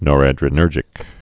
(nôrăd-rə-nûrjĭk)